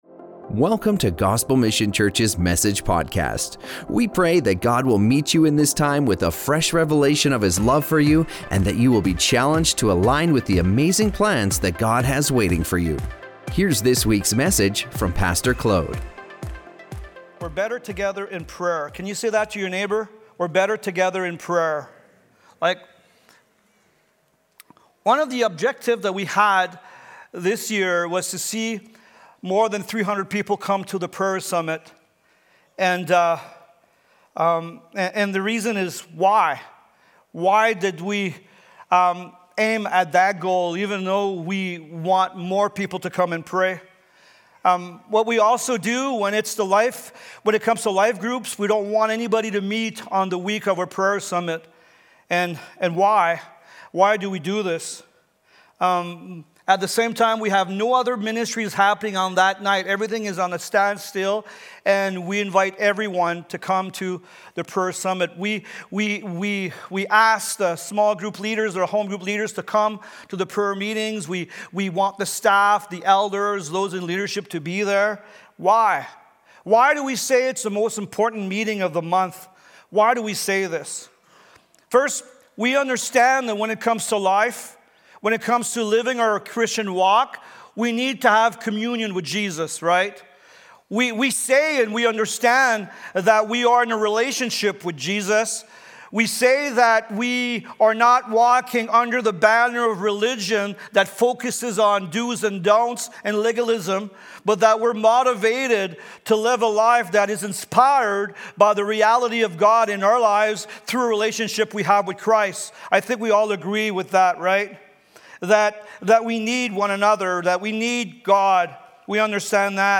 Gospel Mission Church
In this sermon